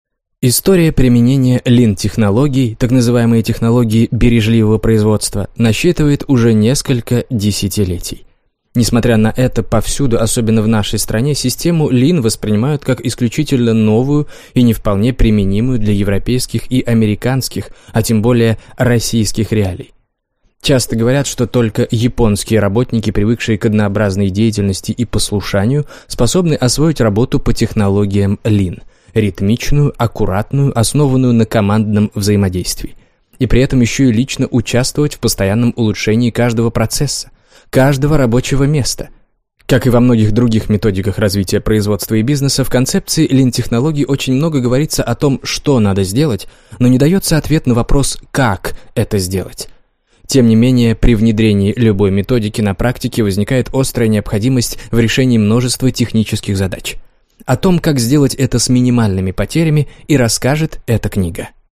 Аудиокнига Философия Lean. Бережливое производство на работе и дома | Библиотека аудиокниг
Бережливое производство на работе и дома Автор Эндрю Штайн Читает аудиокнигу Юрий Грымов.